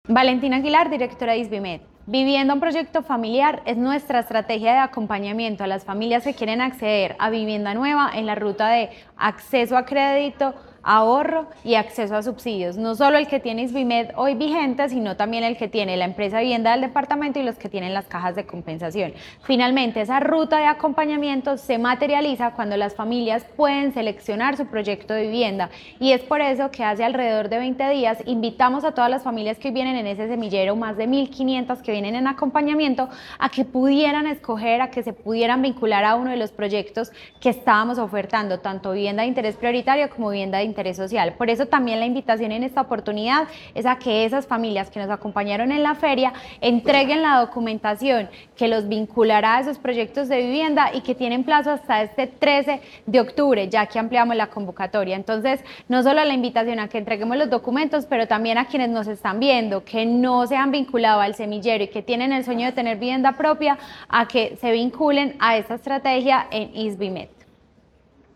Declaraciones directora de Isvimed, Valentina Aguilar Ramírez
Declaraciones-directora-de-Isvimed-Valentina-Aguilar-Ramirez.mp3